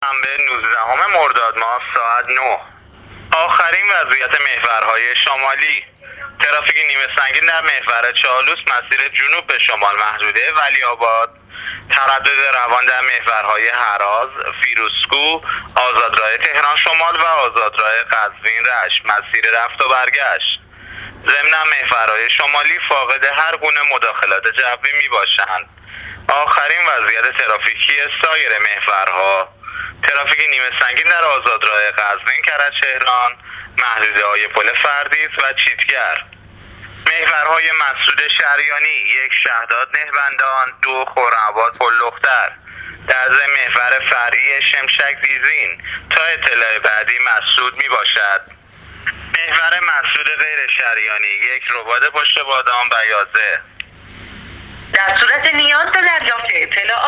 گزارش رادیو اینترنتی از آخرین وضعیت ترافیکی جاده‌ها تا ساعت۹ نوزدهم مرداد: